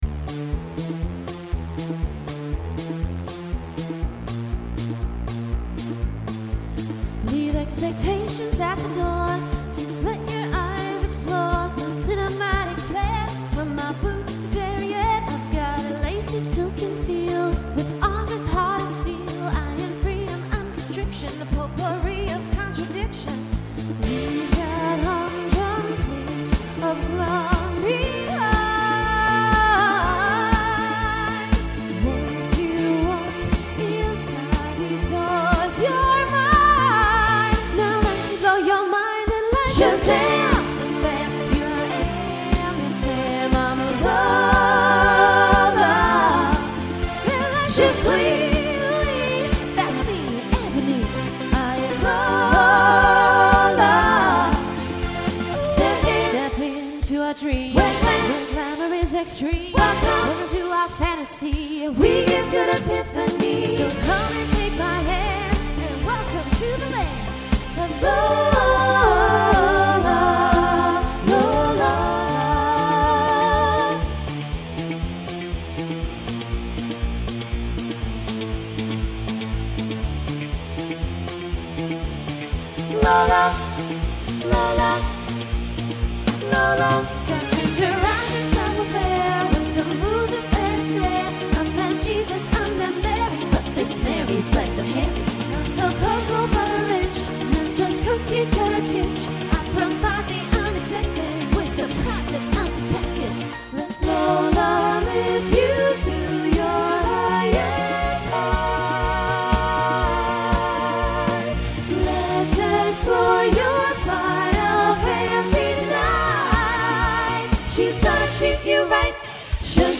Voicing SSA Instrumental combo Genre Broadway/Film
Mid-tempo